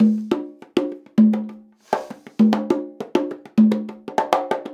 100 CONGAS14.wav